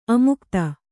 ♪ amukta